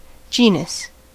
Ääntäminen
IPA : /ˈdʒiːnəs/ IPA : /ˈdʒɛnəs/